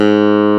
CLAV C2+.wav